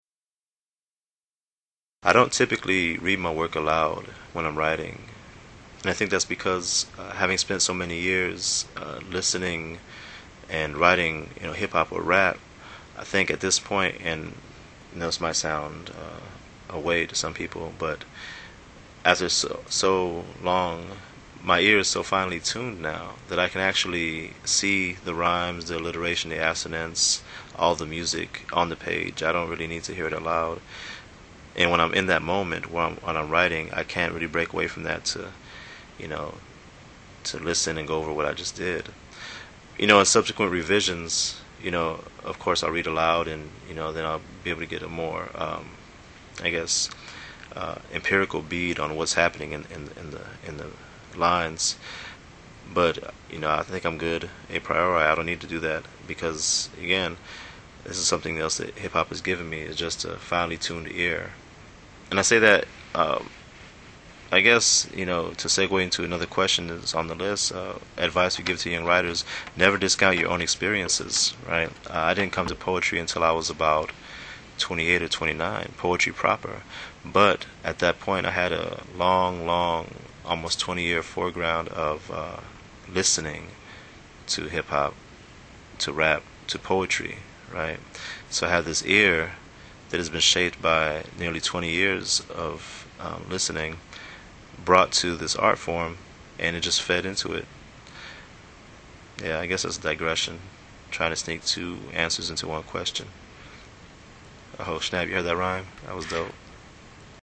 John Murillo Q&A on reading his poems aloud, and some advice | Fishouse